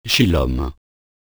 Prononciation du mot "Shilom" ou "Chilom" en français :